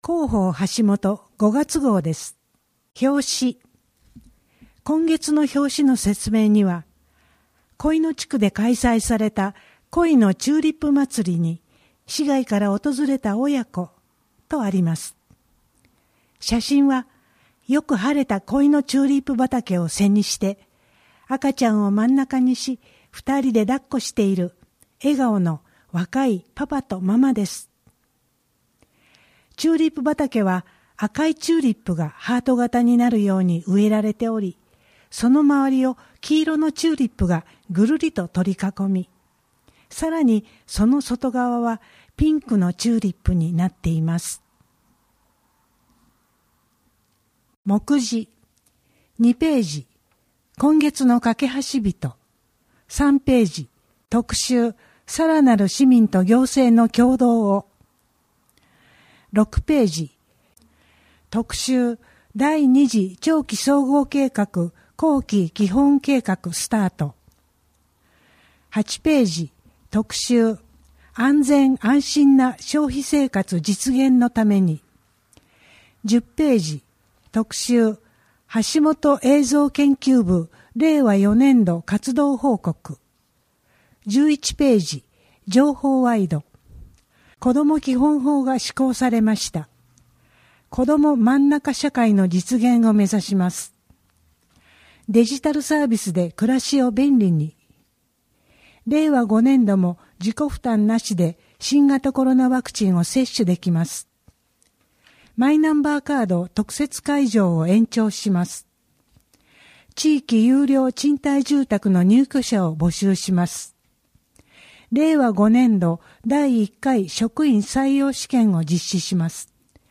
WEB版　声の広報 2023年5月号